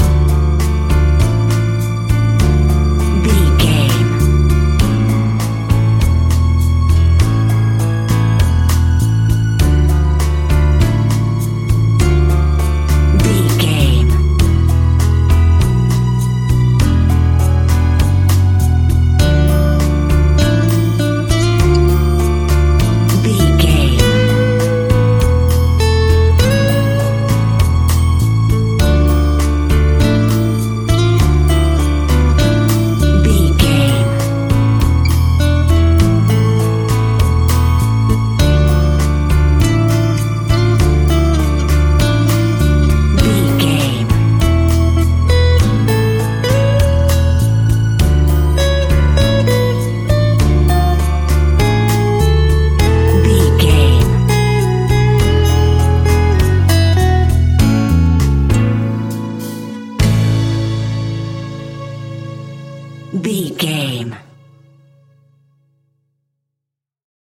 An exotic and colorful piece of Espanic and Latin music.
Ionian/Major
Slow
maracas
percussion spanish guitar